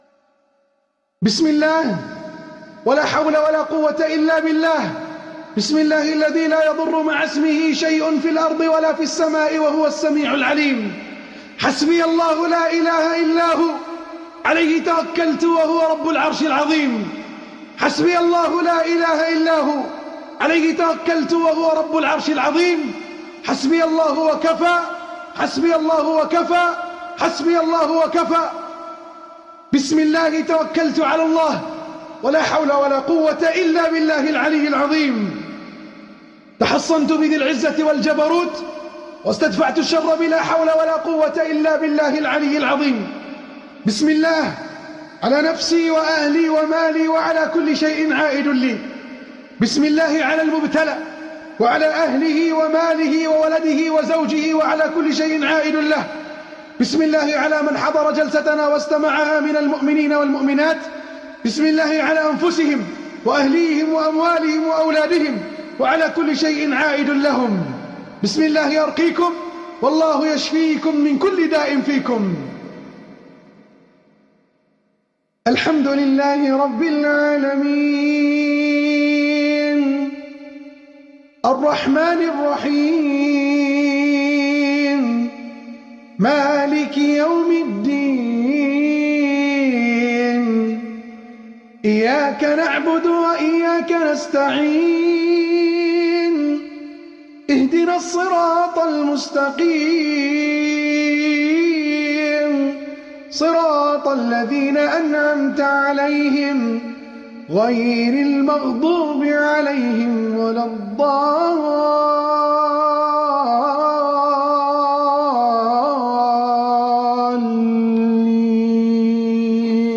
শরীরে লুকিয়ে থাকা জ্বীন ধ্বংসের রুকইয়াহ